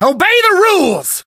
buzz_kill_vo_03.ogg